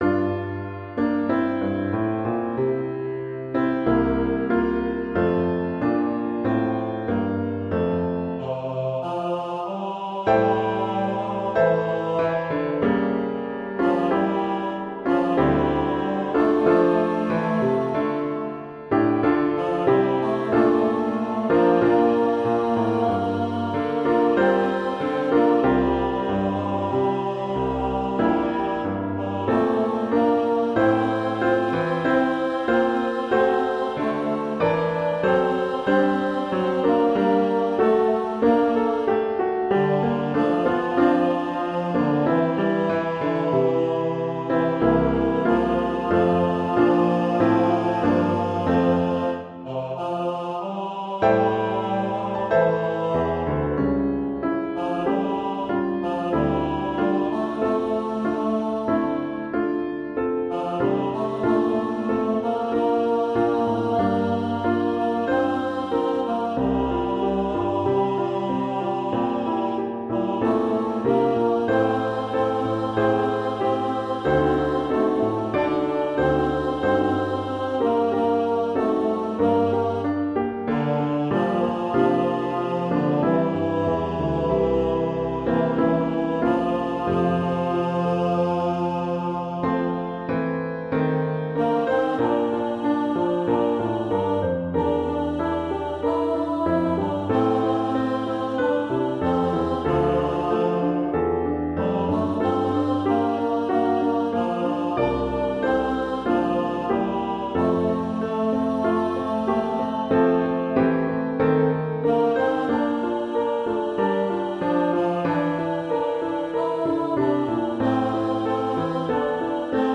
piano
tenor voice